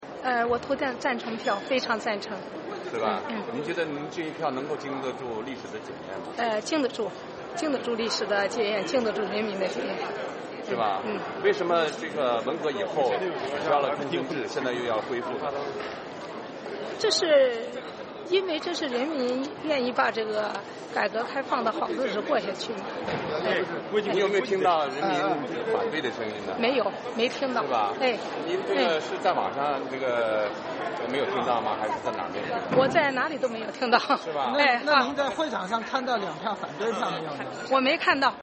山东人大代表窦延丽接受美国之音采访称没有反对修宪案的声音，也没看到表决有反对票